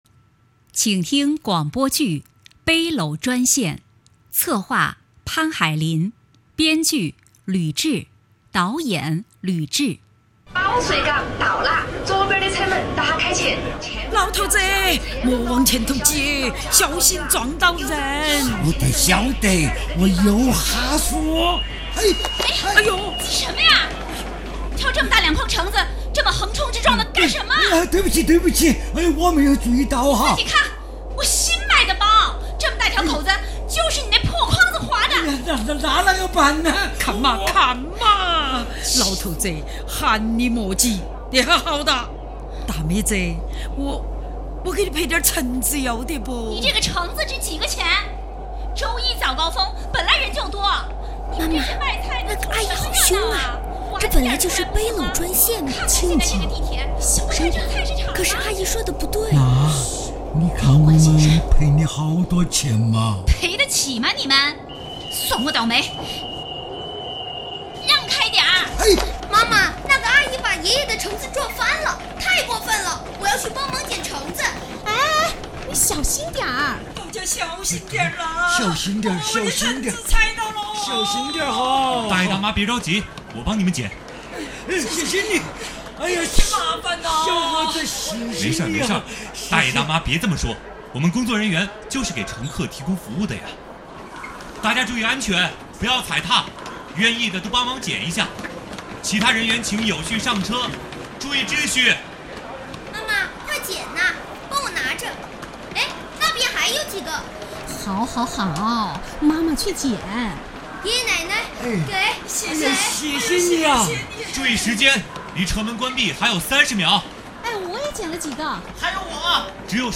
• 广播类型：微广播剧